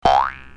clock03.ogg